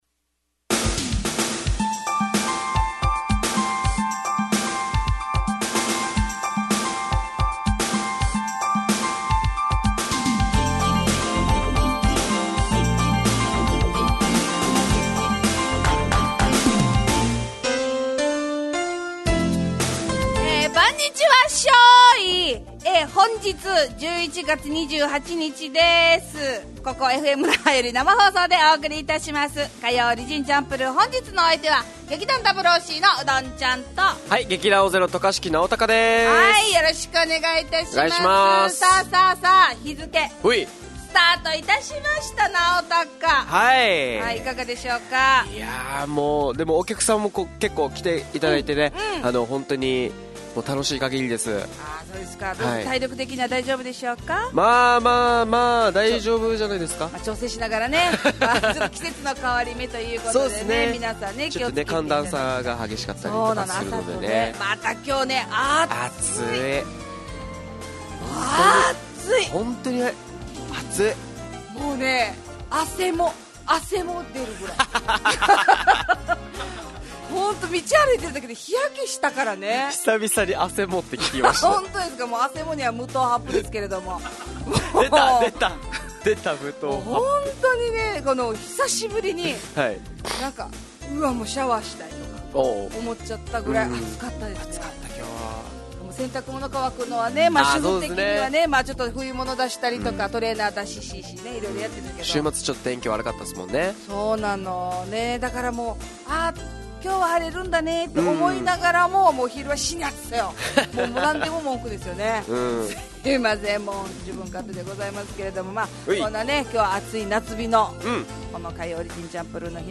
沖縄のFMラジオ局 fm那覇。